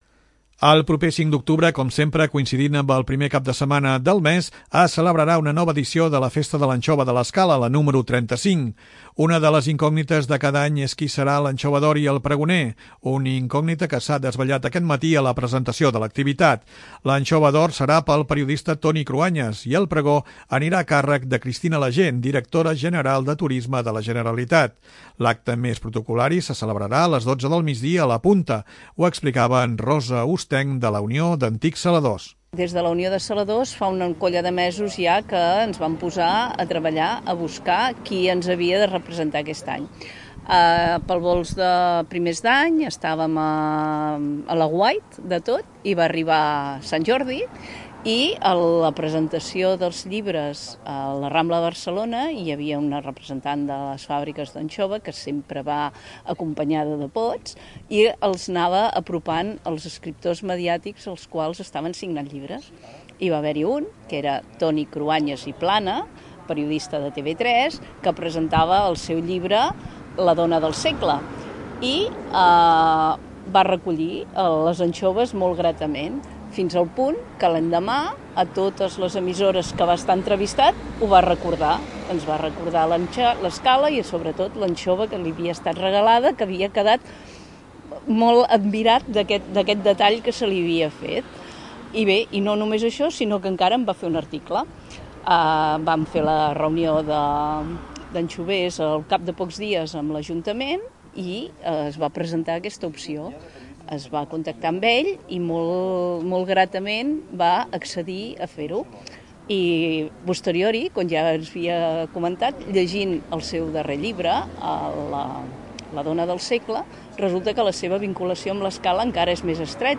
El regidor de Promoció Econòmica, Cultura i Patrimoni, Martí Guinart, parlava de la importància de promoure i difondre arreu l'anxova.